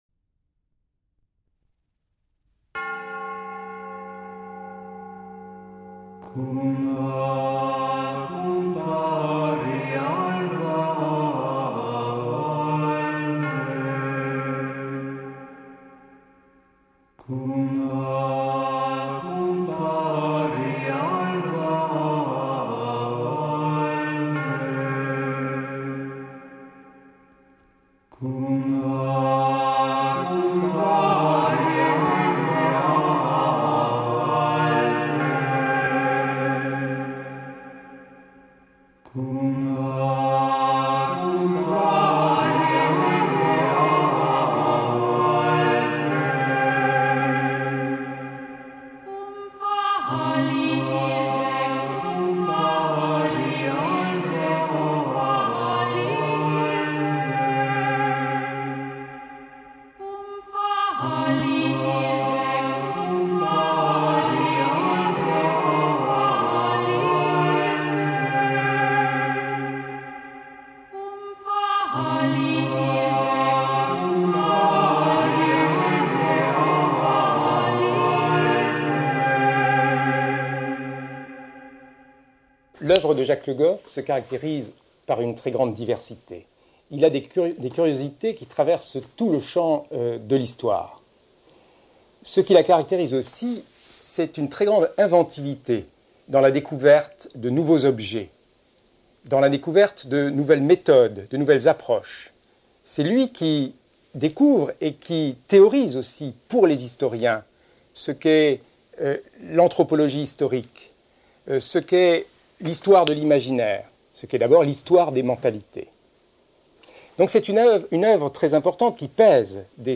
Pour un autre Moyen-Âge : Entretien avec Jacques Le Goff | Canal U